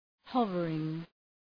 Προφορά
{‘hʌvərıŋ}